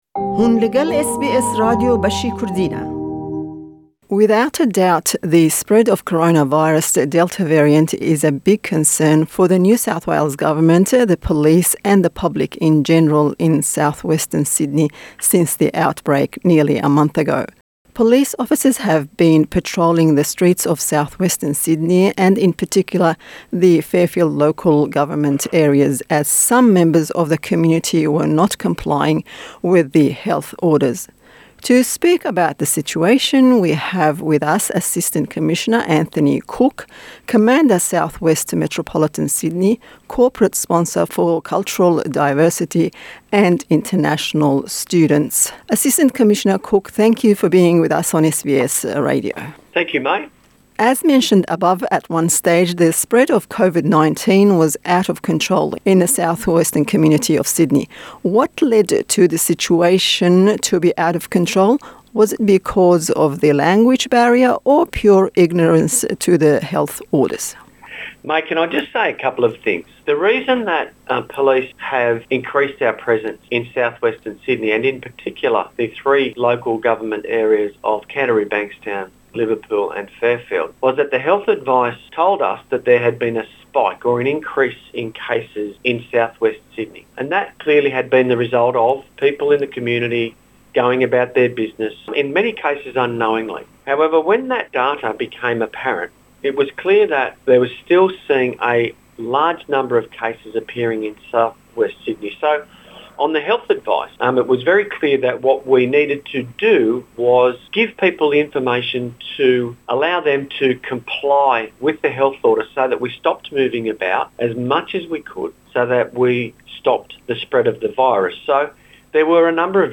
To better understand the situation we speak to NSW Police Assistant Commissioner Anthony Cooke - Commander South West Metropolitan Sydney, Corporate Sponsor for Cultural Diversity and International Students.